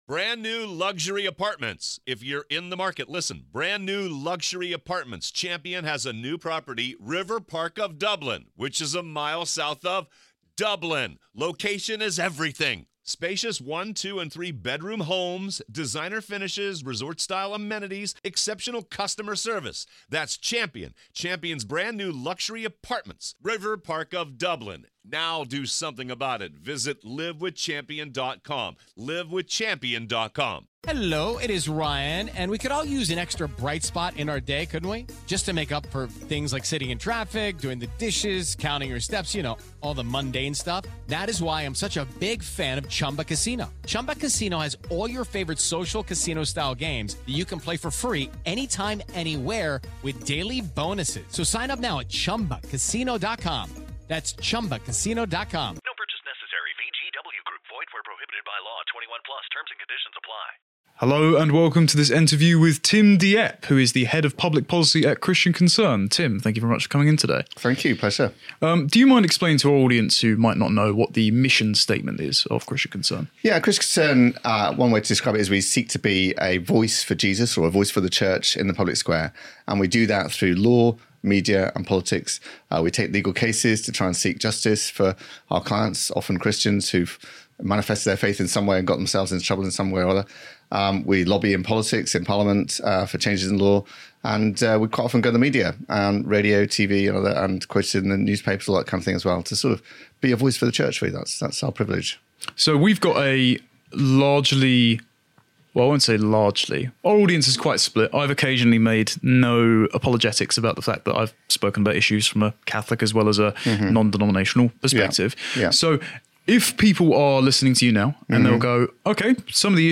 What's Killing Britain? | Interview